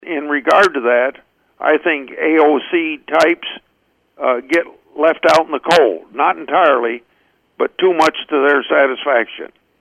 Grassley participated in an exclusive interview with